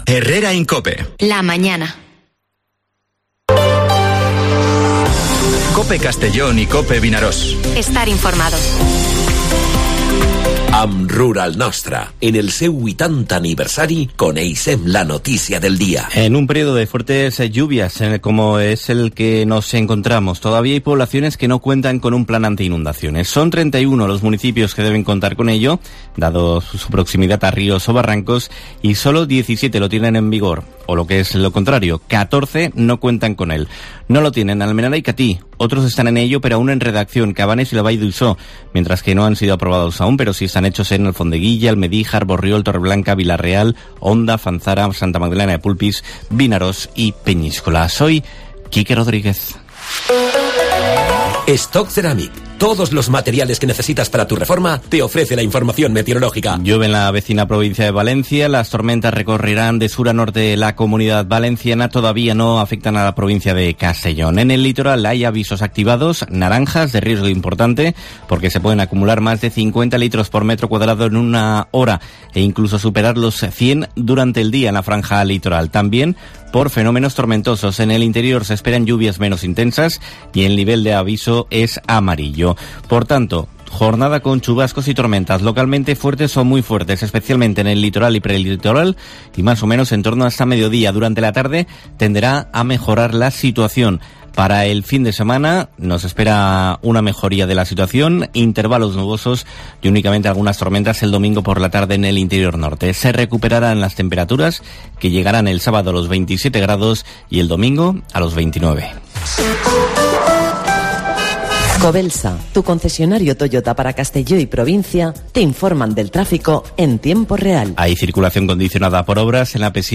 Informativo Herrera en COPE en la provincia de Castellón (15/09/2023)